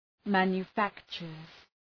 Shkrimi fonetik{,mænjə’fæktʃərz}